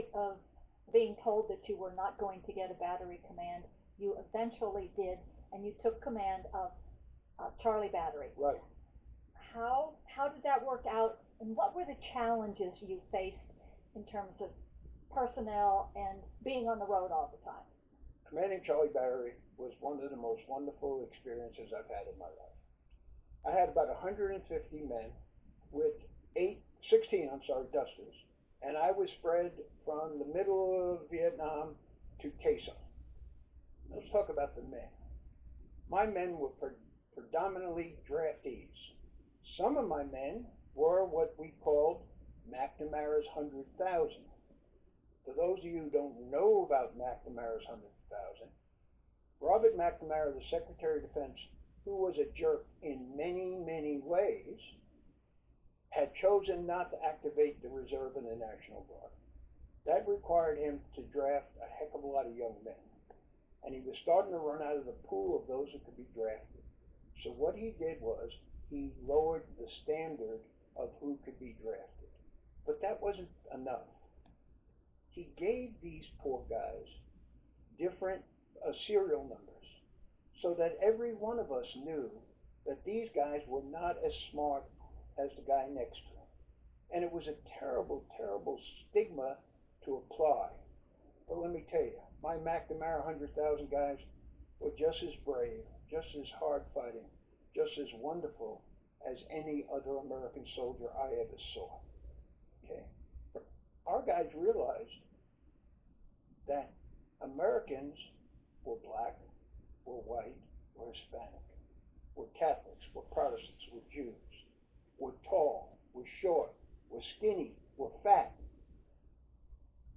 Audio Archive of their interview